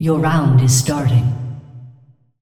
vo-anncr-fem1-tournaments-round-start-01.ogg